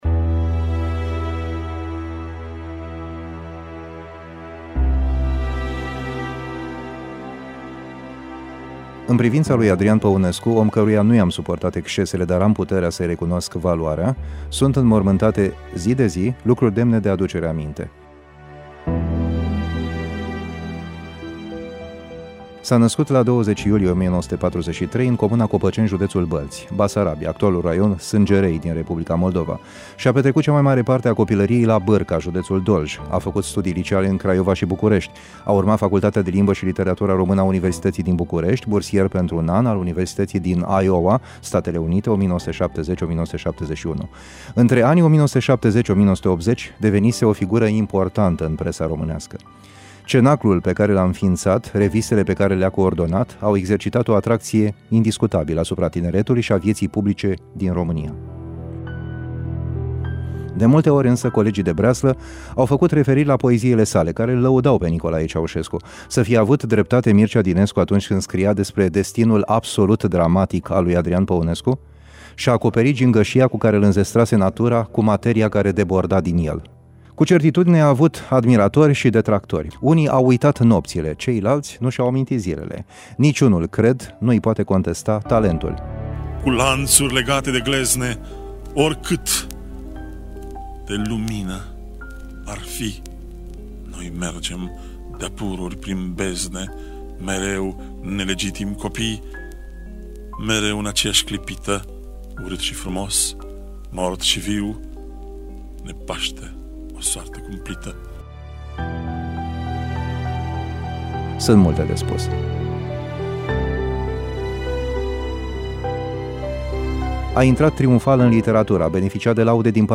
documentar